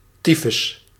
Ääntäminen
Ääntäminen France: IPA: /ti.fys/ Haettu sana löytyi näillä lähdekielillä: ranska Käännös Ääninäyte Substantiivit 1. tyfus Suku: m .